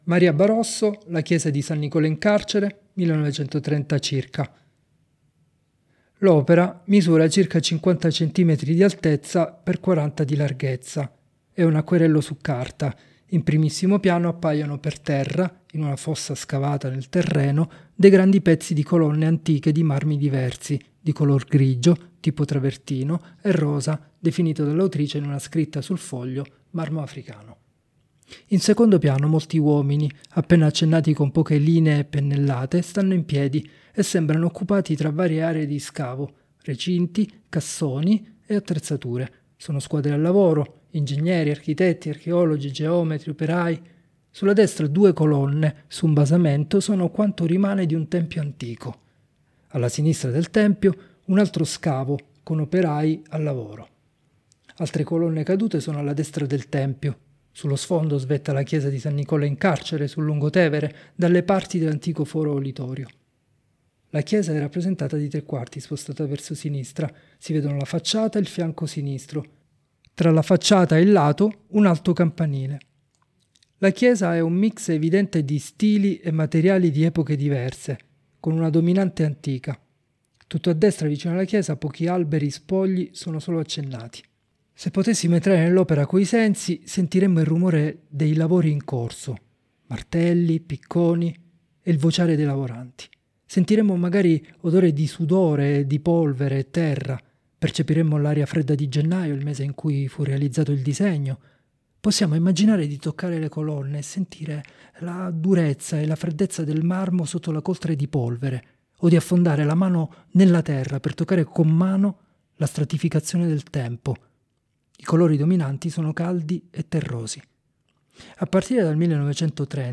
Audiodescrizioni sensoriali opere selezionate: